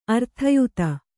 ♪ arthayuta